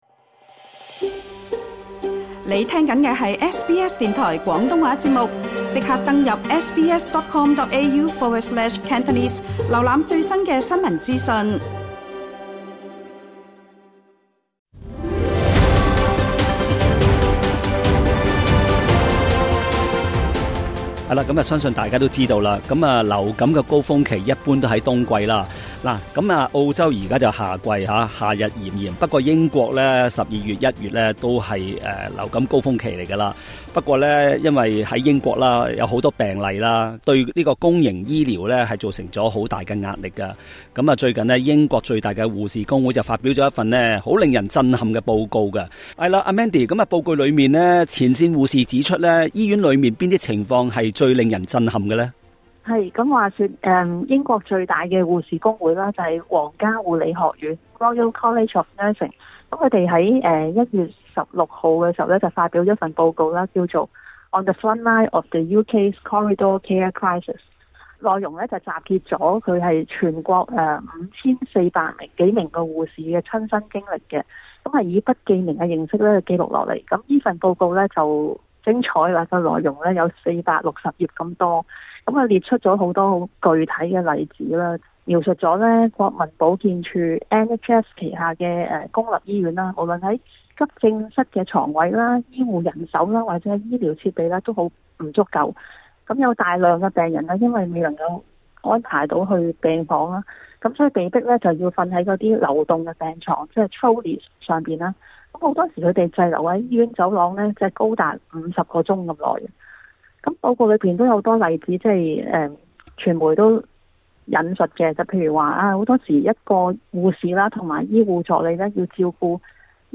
Source: AAP / PA SBS廣東話節目 View Podcast Series Follow and Subscribe Apple Podcasts YouTube Spotify Download (5.97MB) Download the SBS Audio app Available on iOS and Android 英國冬季流感大爆發，曝露出英國公營醫療系統千瘡百孔。